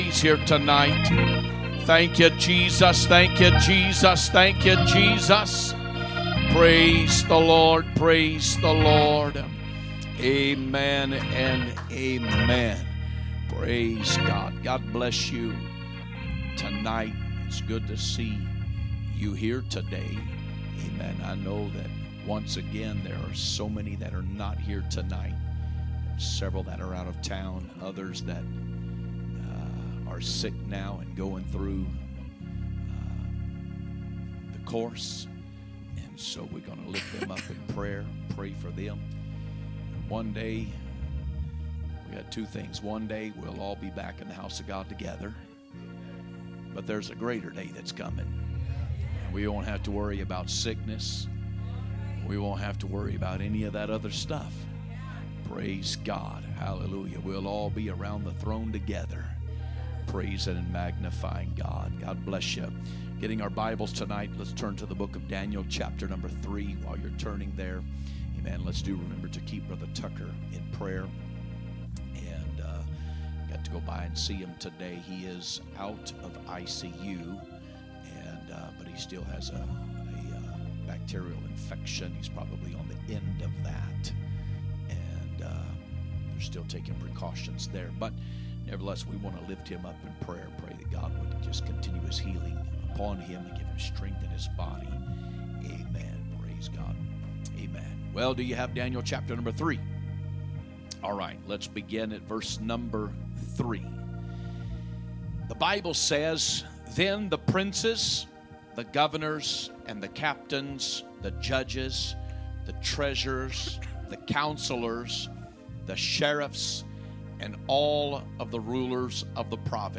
Wednesday Service
2025 Sermons